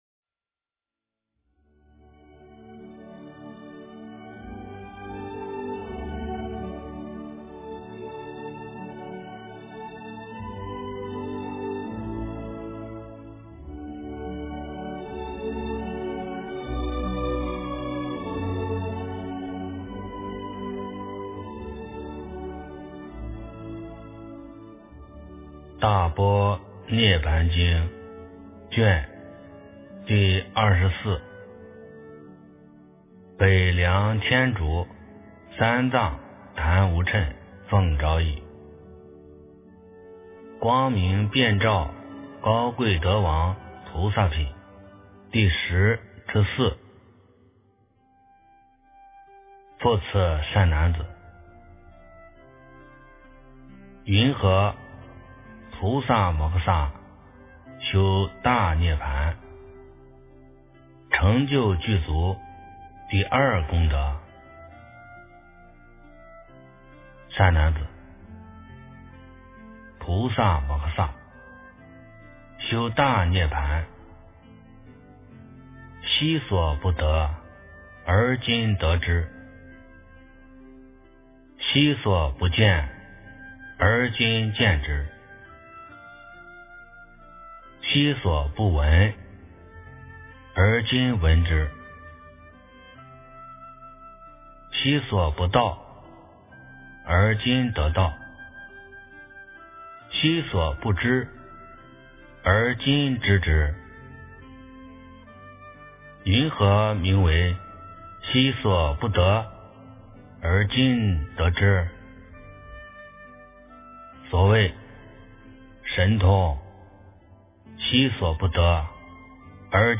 大般涅槃经24 - 诵经 - 云佛论坛